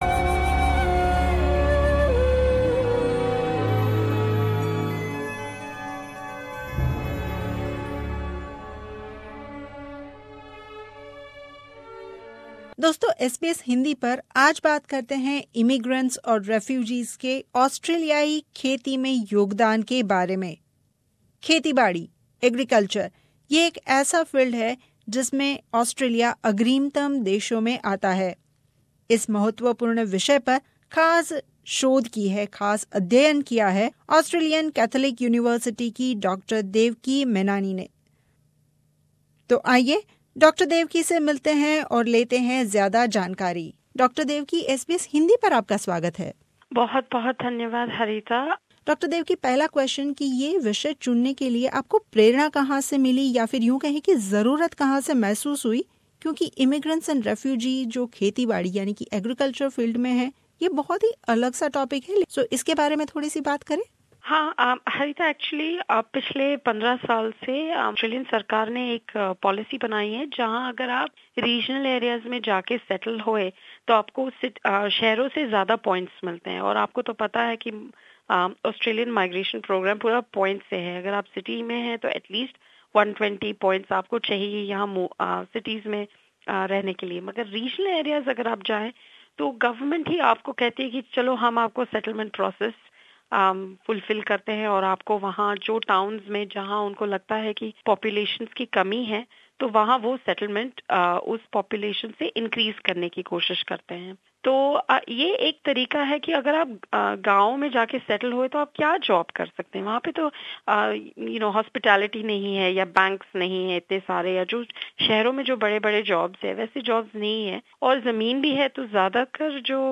भेटवार्ता